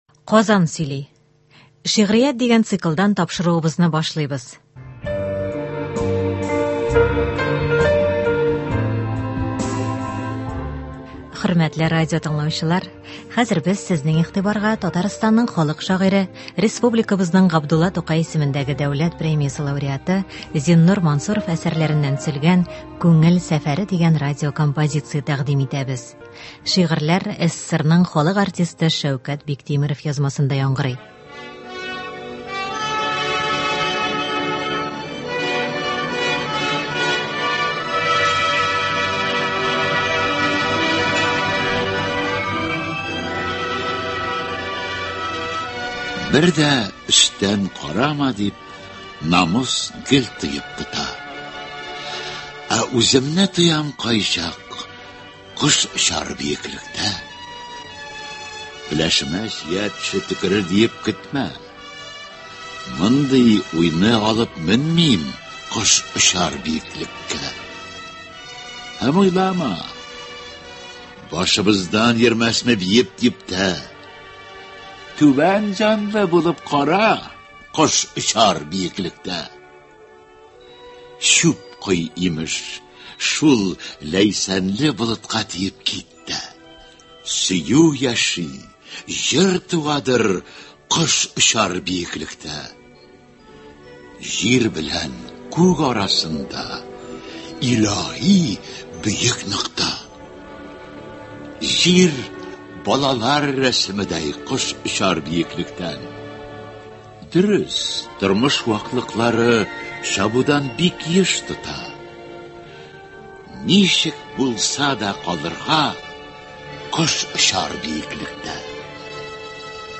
Без сезнең игътибарга Татарстанның халык шагыйре, Республикабызның Г.Тукай исемендәге Дәүләт премиясе лауреаты Зиннур Мансуров әсәрләреннән төзелгән “Күңел сәфәре” дигән радиокомпозиция тәкъдим итәбез. Шигырьләр СССРның халык артисты Шәүкәт Биктимеров язмасында яңгырый.